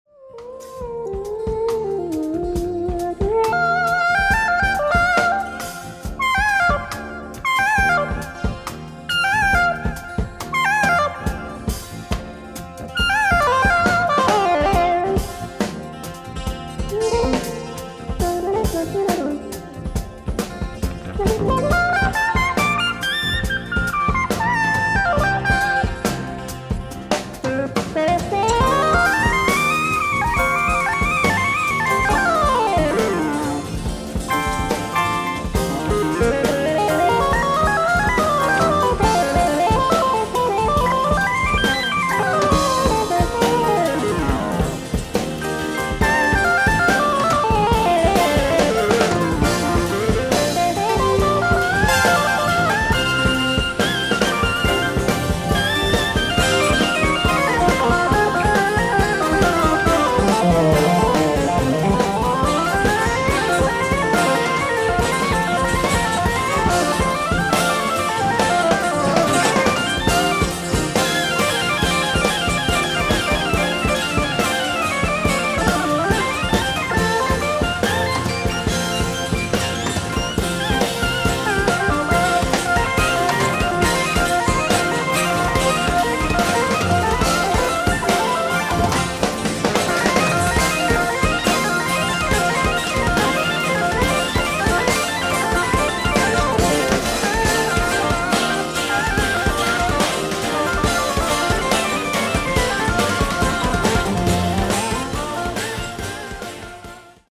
ライブ・アット・ニース・ジャズフェスティバル、ニース、フランス 07/13/1985
この夏（２０２５年）の再放送ステレオ音源！！